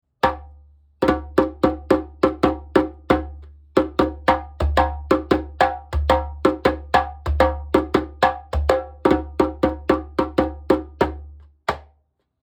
Used Burkina Faso 33cm - TDF『東京のジェンベ販売・張替・修理の専門店』
ボディ ブルキナファソ 打面 33cm、高さ63cm、重量9.1kg 木材 ジャラ 皮 ヤギ皮 マリ 中厚 縦
誕生から20年近く経過して経年良化している、レグの形状と相まって中音は深く温かく、スラップも味わい深いサウンドです。